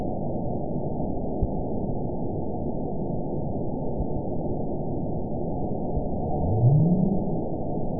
event 913794 date 04/20/22 time 20:20:46 GMT (3 years ago) score 9.06 location TSS-AB01 detected by nrw target species NRW annotations +NRW Spectrogram: Frequency (kHz) vs. Time (s) audio not available .wav